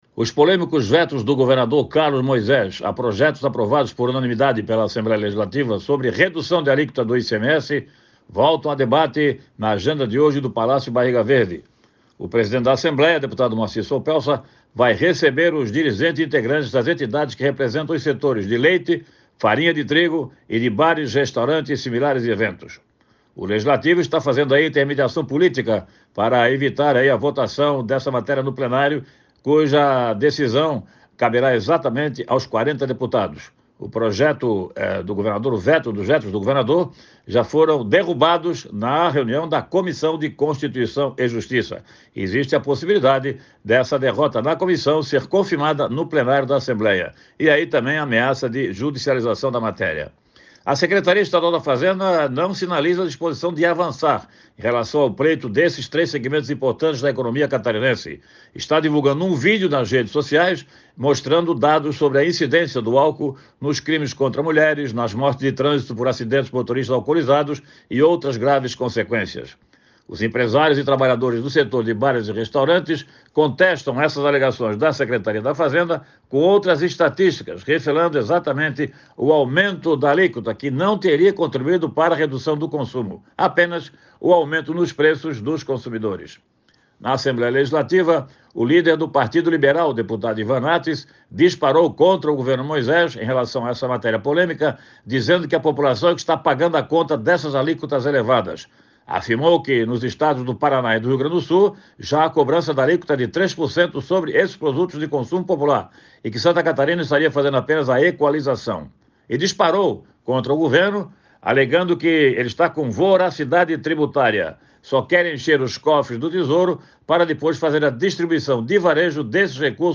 Jornalista aborda reuniões do governador de Santa Catarina com presidentes de partidos em Brasília